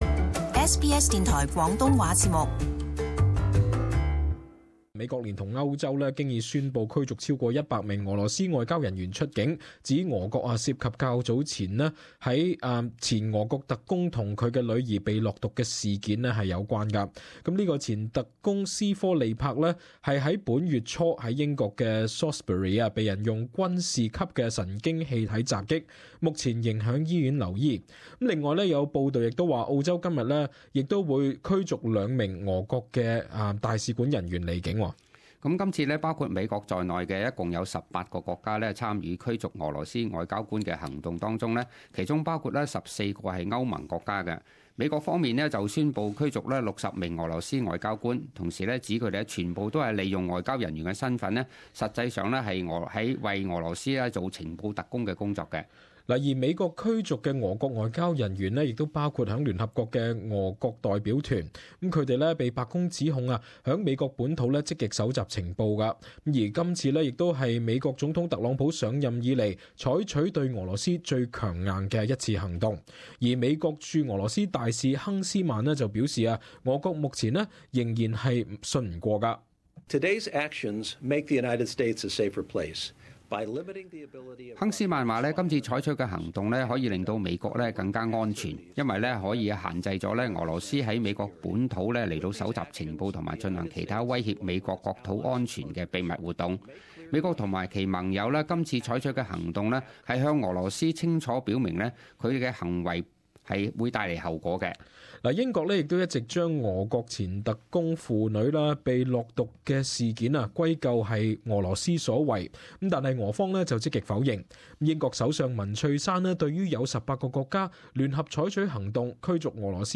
【时事报导】18 个国家宣布驱逐百多名俄罗斯外交官离境